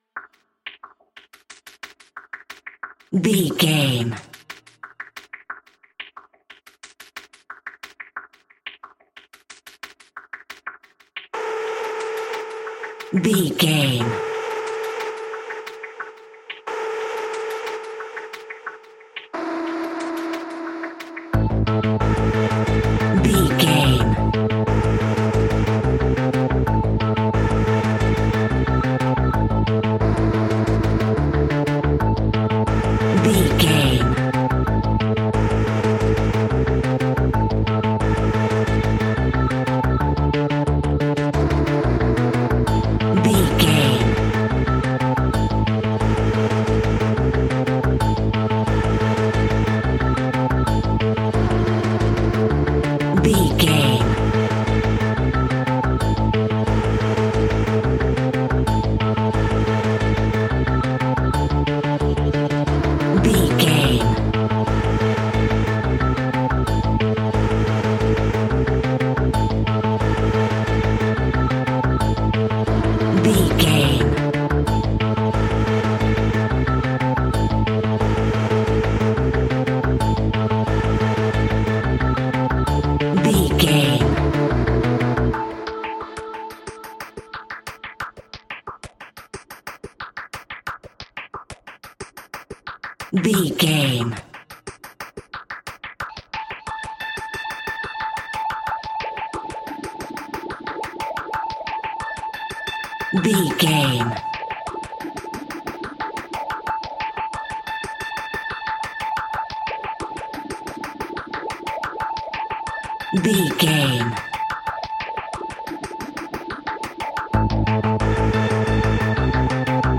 Aeolian/Minor
scary
ominous
dark
eerie
synthesiser
drums
percussion
instrumentals
horror music